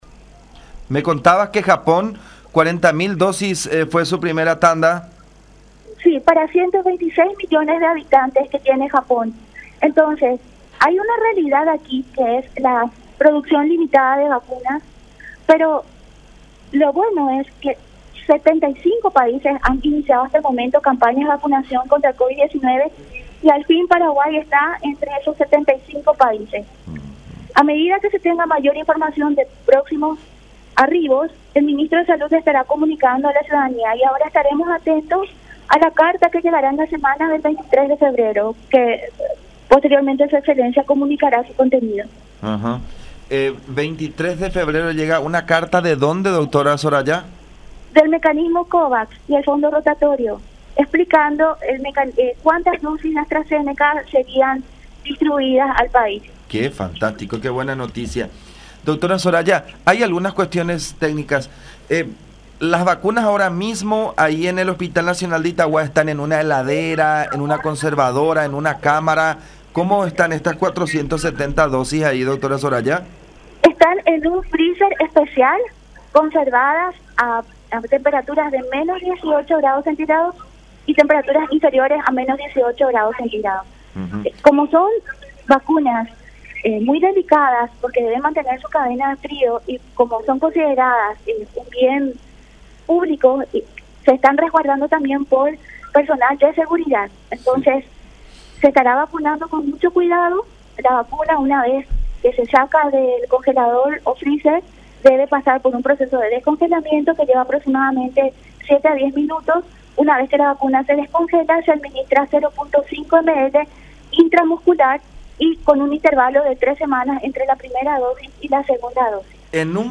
“Con las vacunas se busca reducir la tasa de mortalidad y los ingresos a cuidados intensivos. Nos sentimos emocionados por haber iniciado el proceso de vacunaciones. Es una vacuna segura, tiene baja incidencia de efectos secundarios”, destacó Soraya Araya, directora del PAI, en charla con La Unión.